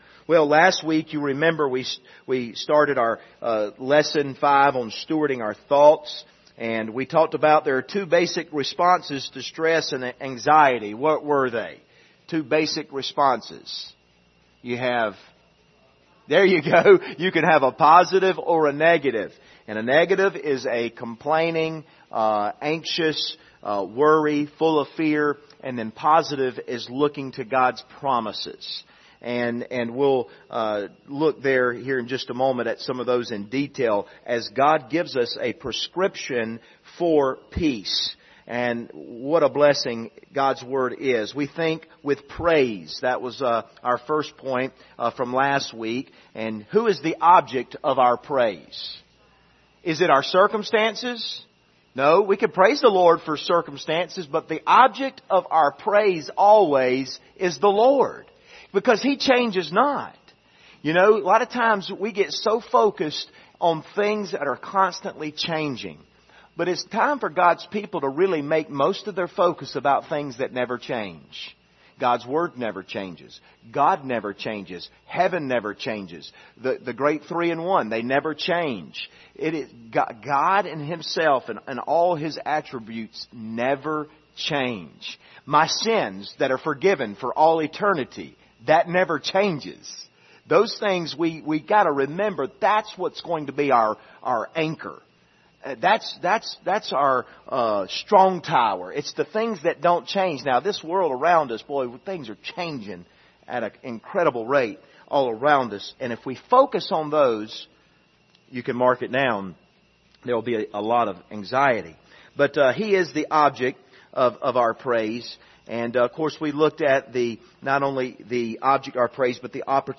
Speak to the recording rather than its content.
Philippians 4:4-8 Service Type: Wednesday Evening « The Lord’s Table Thessalonica